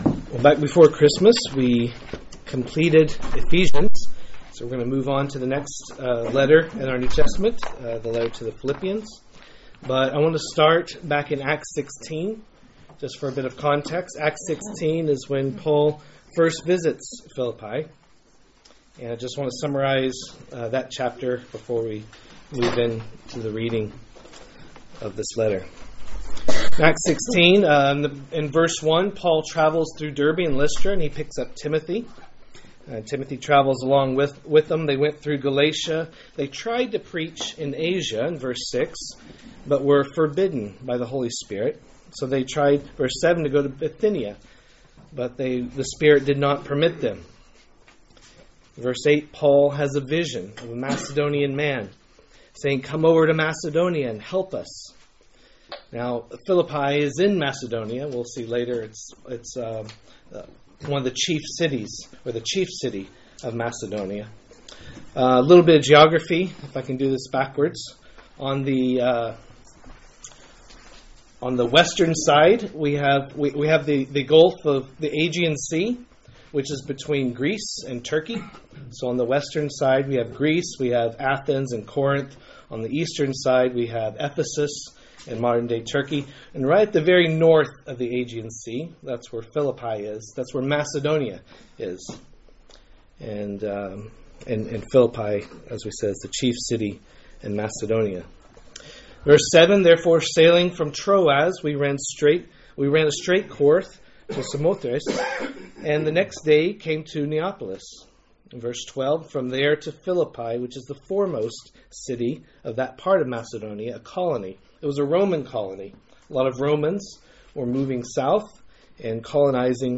A message from the series "Philippians Series 2." Philippians 1:1-11